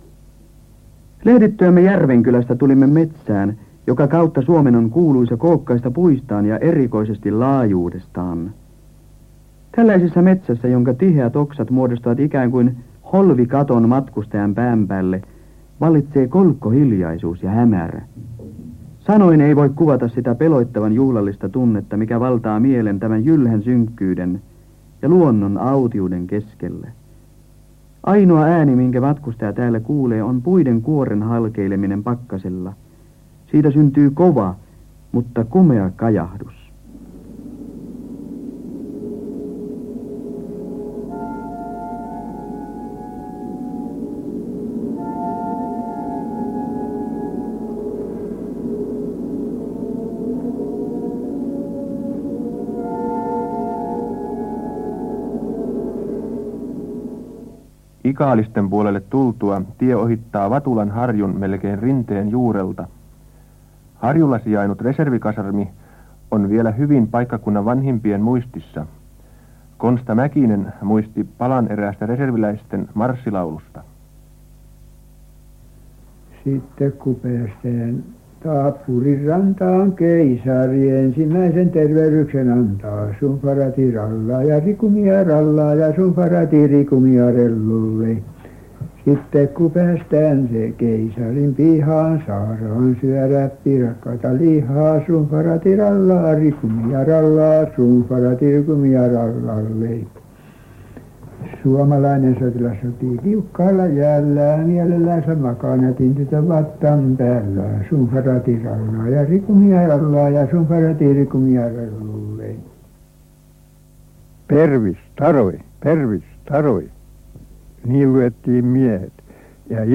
Radionauhoite